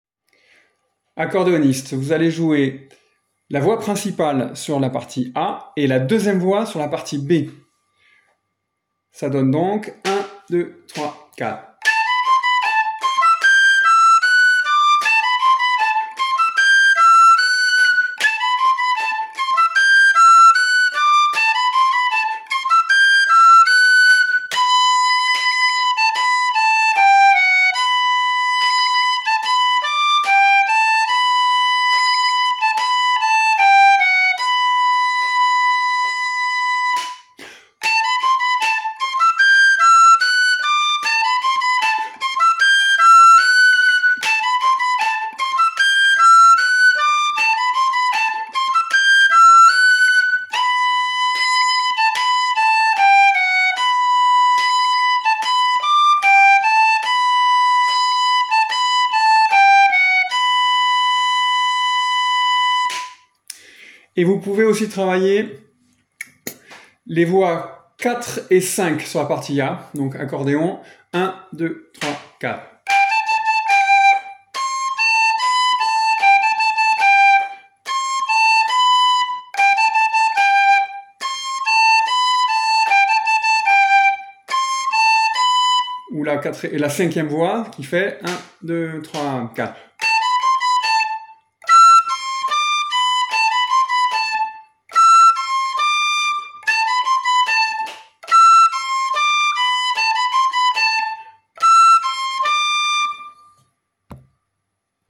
les-boyaux-accordéons.mp3